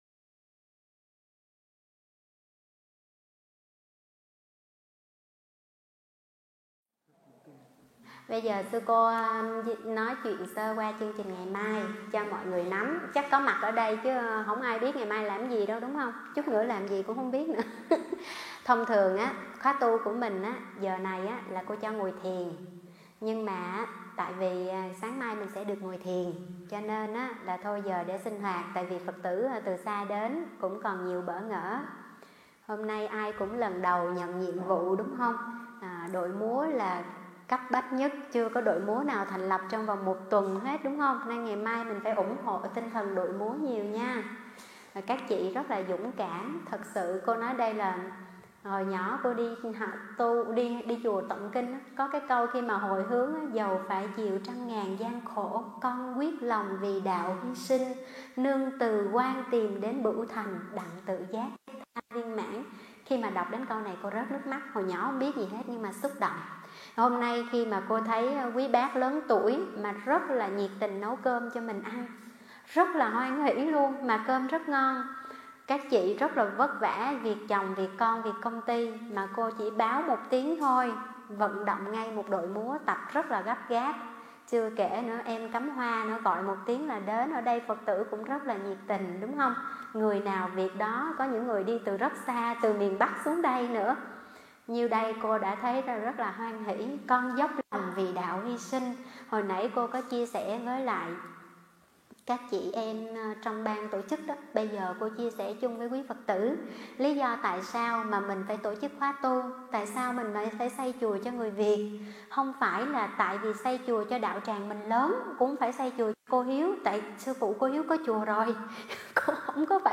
thuyết pháp Tu trong mùa dịch Covid-19